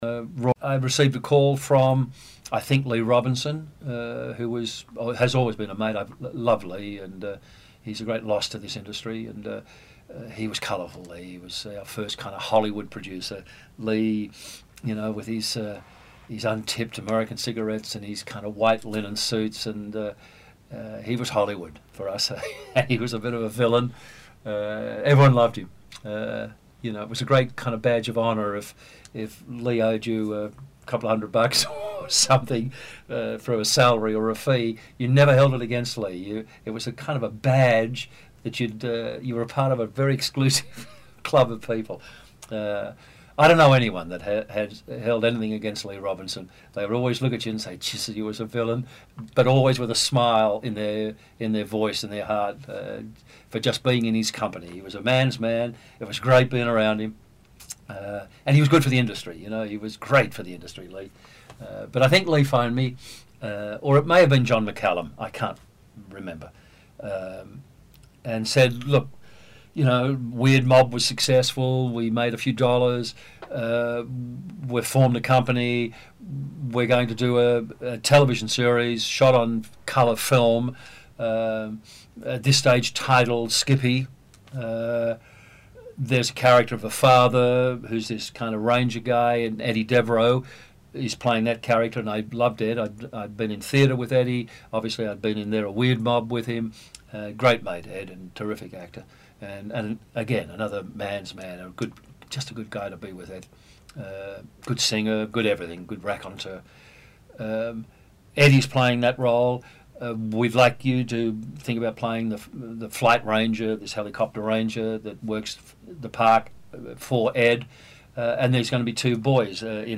Tony Bonner: oral history
82625-tony-bonner-oral-history